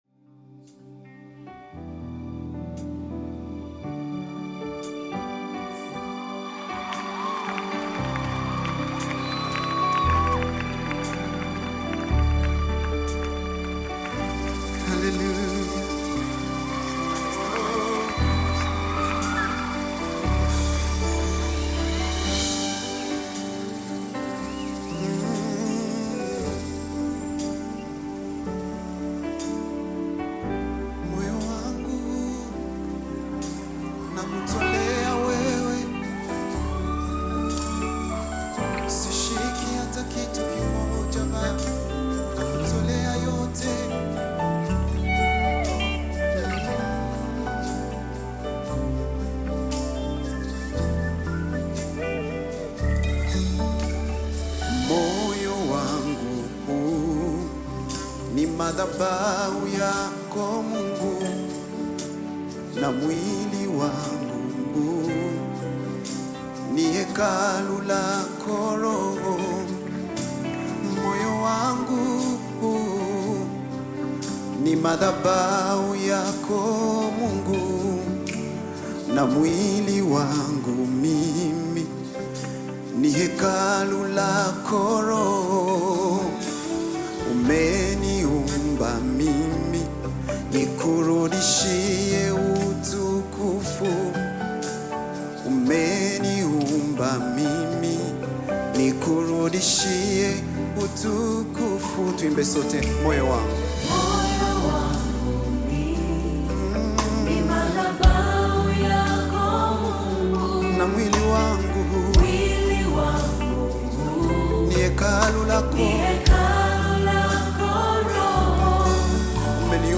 African Music
Tanzanian gospel singers
praise and worship song